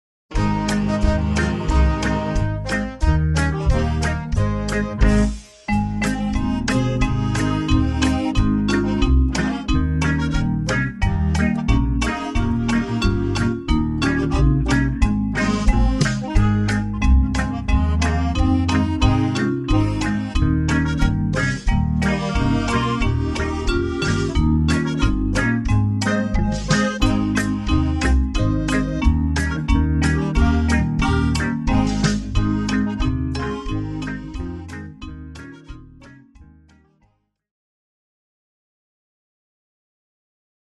Jautra, divbalsīga dziesmiņa ar fonogrammu